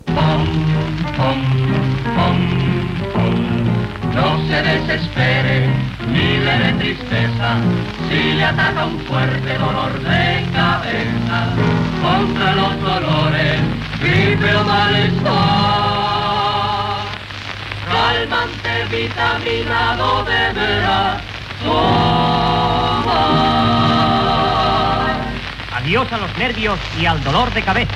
Anunci cantat